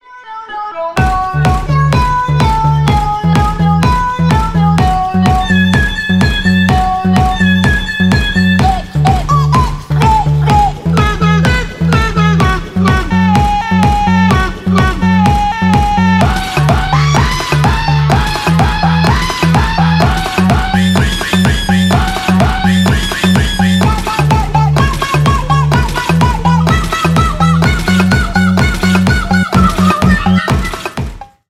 Ремикс # Поп Музыка
весёлые